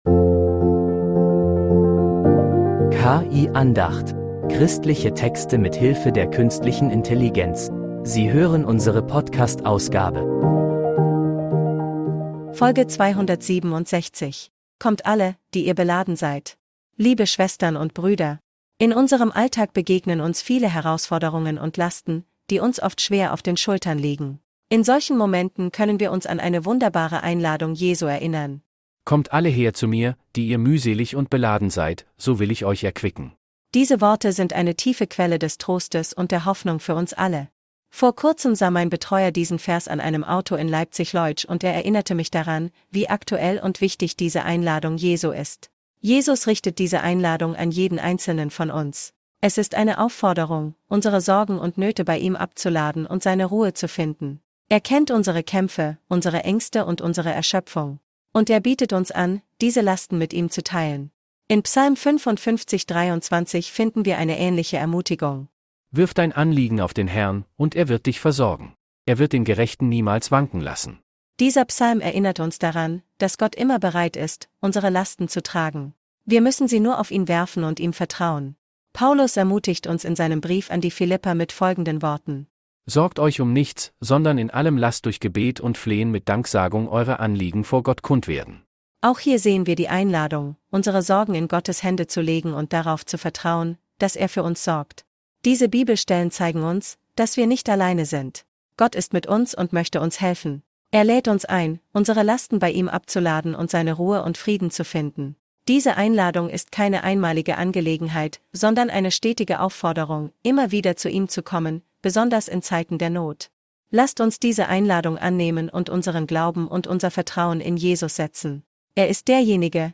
In dieser Andacht wird die Einladung Jesu betont, unsere Lasten